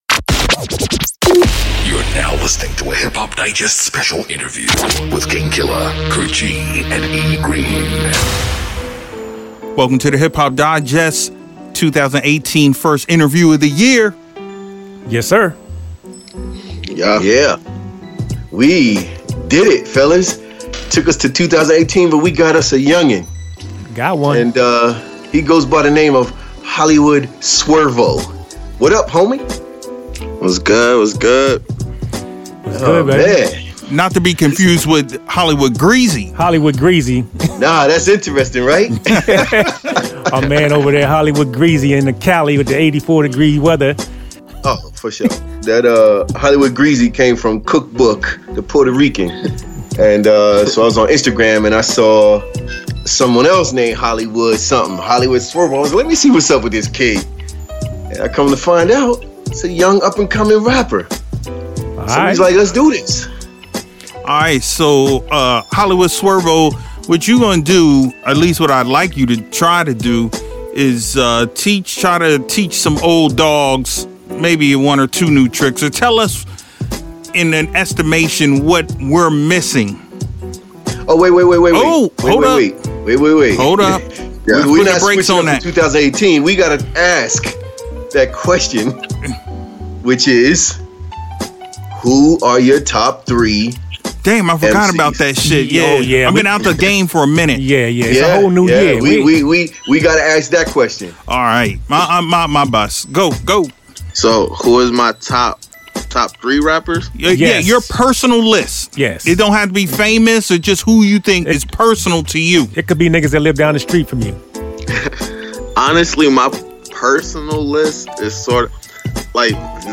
We’ve been asking for a long time to be able to talk to one of the youngin’s doin the young thing. Without messing it up for you, this was an educational conversation.